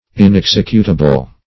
Search Result for " inexecutable" : The Collaborative International Dictionary of English v.0.48: Inexecutable \In*ex"e*cu`ta*ble\, a. [Pref. in- not + executable: cf. F. inex['e]cutable.] Incapable of being executed or performed; impracticable; infeasible.